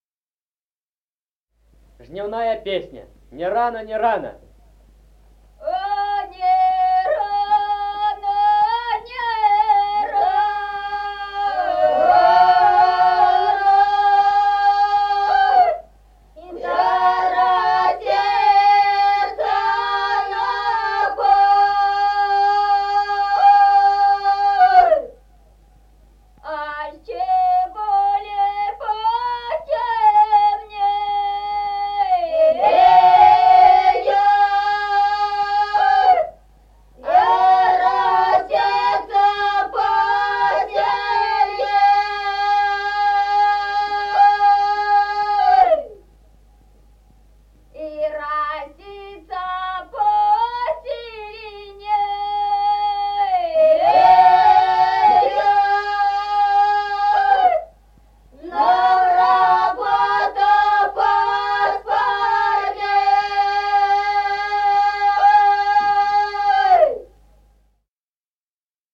Музыкальный фольклор села Мишковка «А не рано, не рано», жнивная.